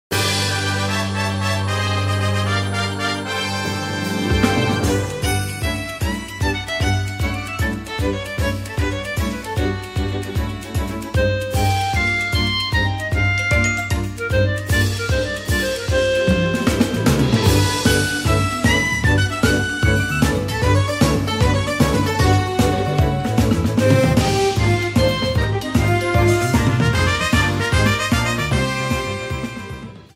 trimmed to 30 seconds, added fade out